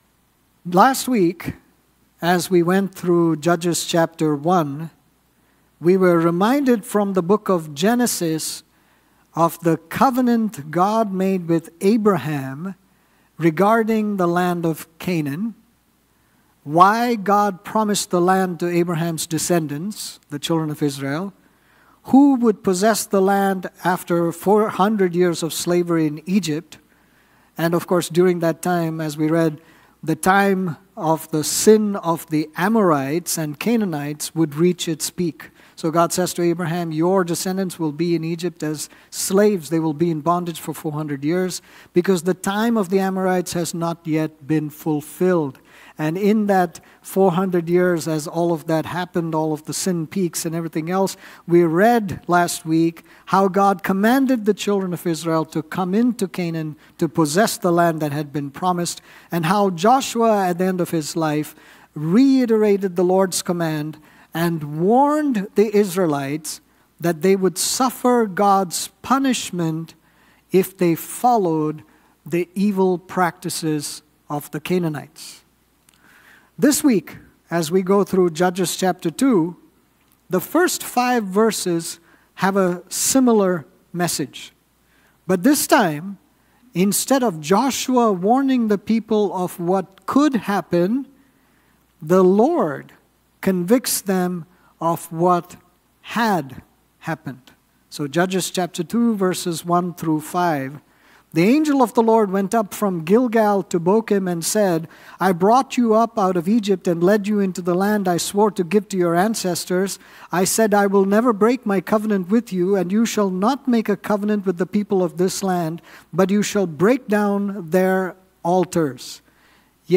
Sermons | New Life Fellowship Church